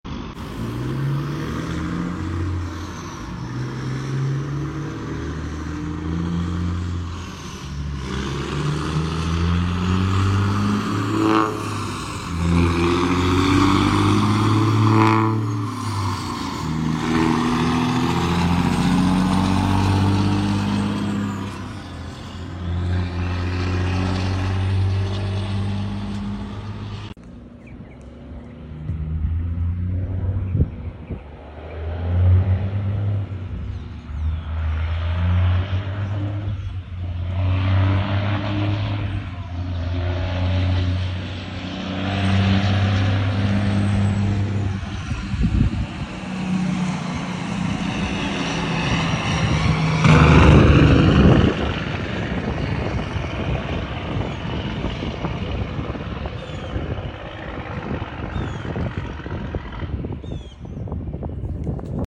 The first clip is a Cat Powered glider 389 peterbilt. The second clip is a weight reduced Cummins ISX 15 Powered 389 peterbilt.